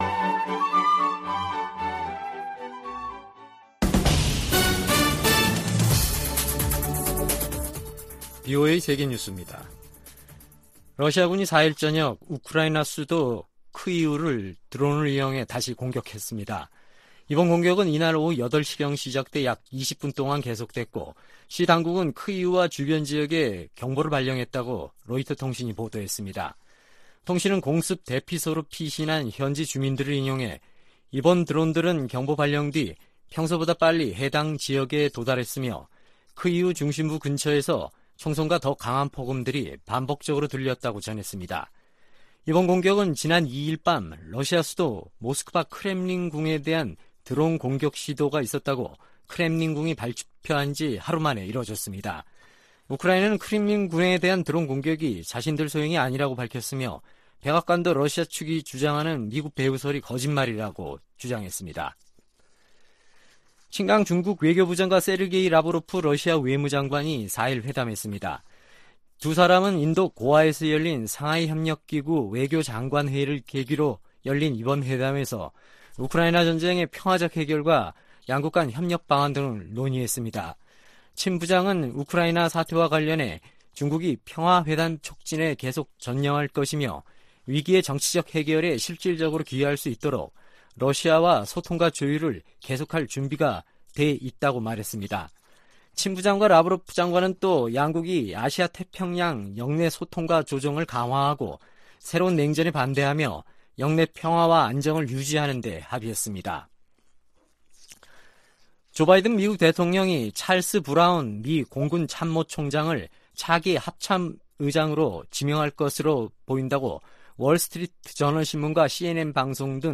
VOA 한국어 아침 뉴스 프로그램 '워싱턴 뉴스 광장' 2023년 5월 6일 방송입니다. 기시다 후미오 일본 총리 방한이 미한일 삼각공조를 더욱 공고히 할 것이며, 조 바이든 행정부의 노력이 결실을 맺고 있다고 미국의 전문가들은 평가하고 있습니다. 미 국방부가 북한의 미사일 발사 정보 공유를 위해 역내 동맹과 접촉 중이라고 밝혔습니다. 에브릴 헤인스 미 국가정보국장(DNI)은 북한의 암호화폐 탈취를 국가안보 위협으로 규정했습니다.